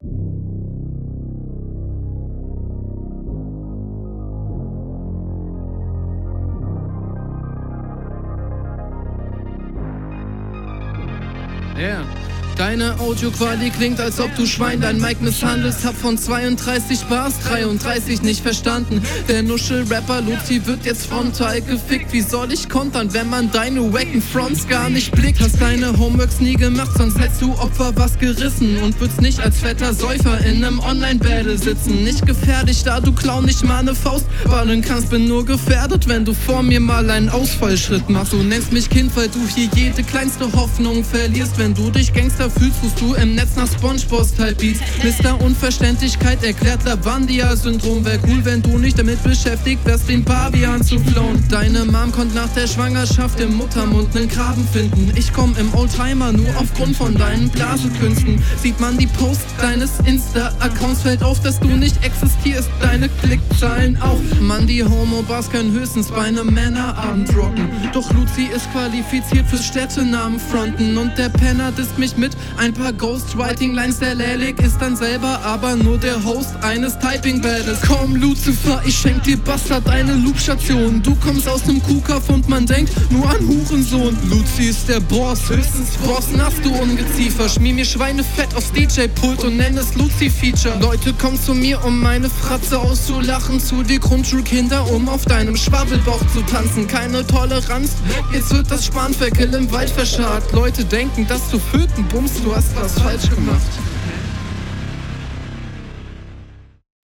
mix/master ist besser, aber auch kein genuss. beat sehr unterpräsent und da passiert was ganz …